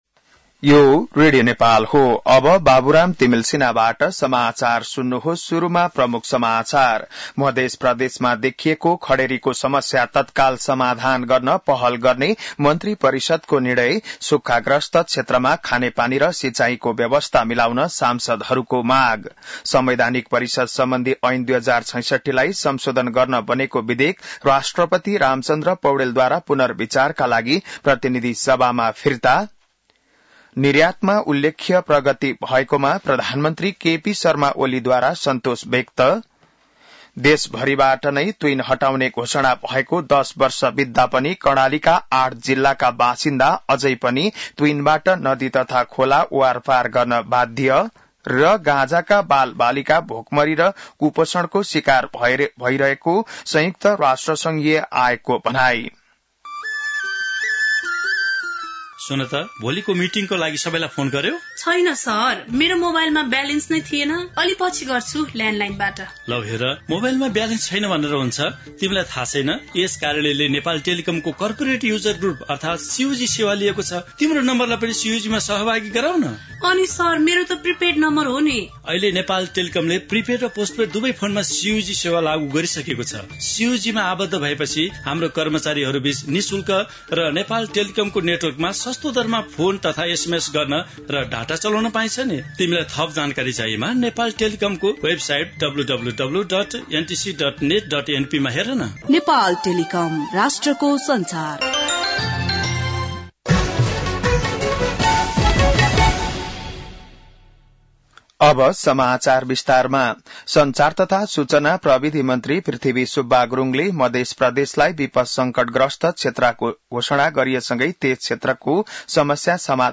बिहान ७ बजेको नेपाली समाचार : ९ साउन , २०८२